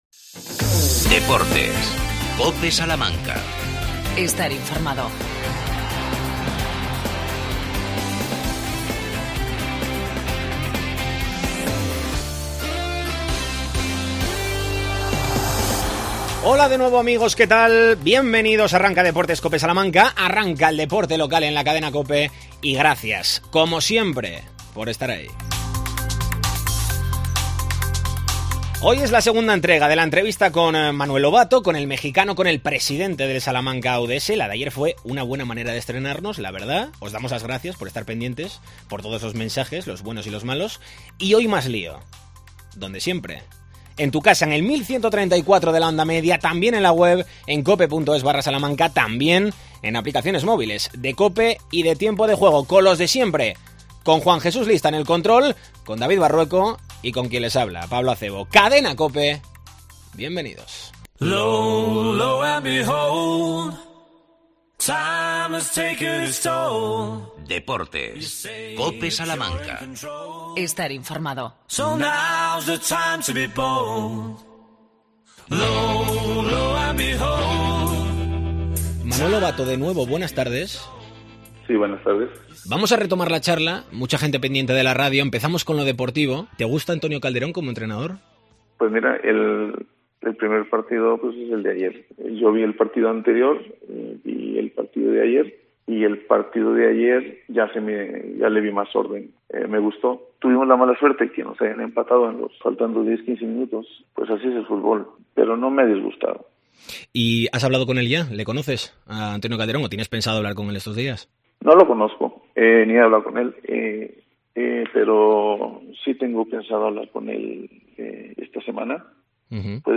Segunda parte de la entrevista